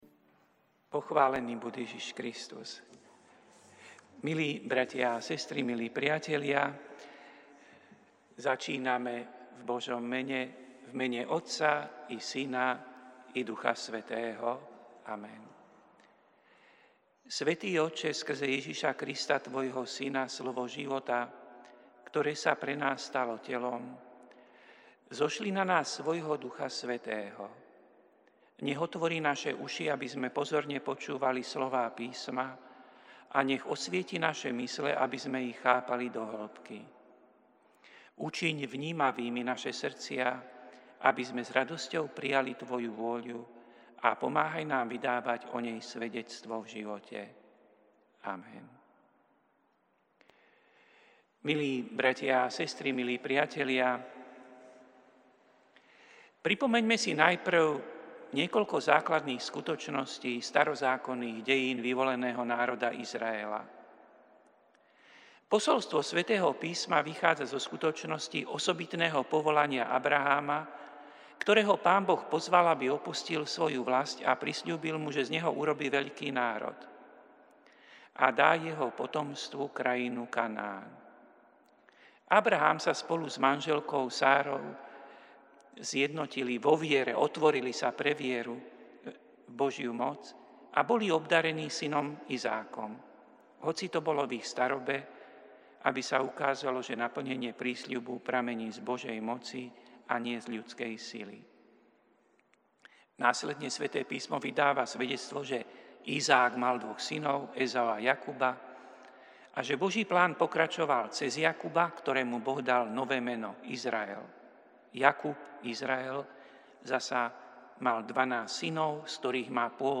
Prinášame plný text a audio záznam z Lectio divina, ktoré odznelo v Katedrále sv. Martina 5. novembra 2025.